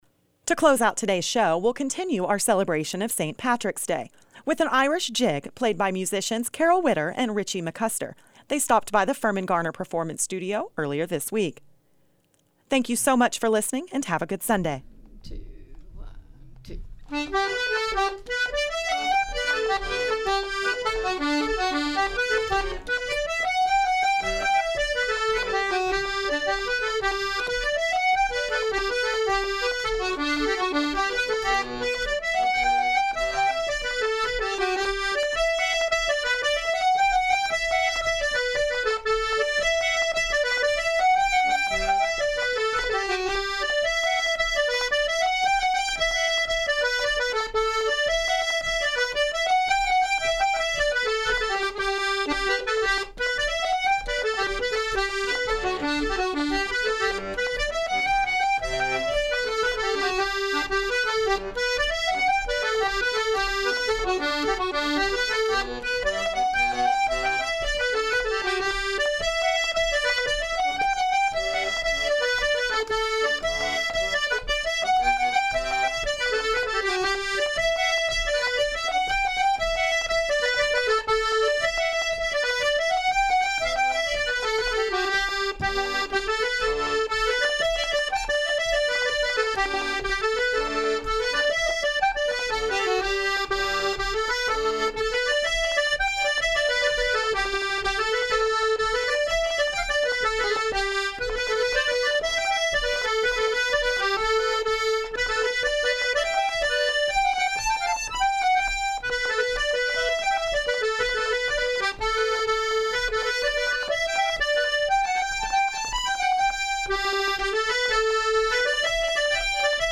Another Irish Folk Tune For The Holiday
traditional Irish song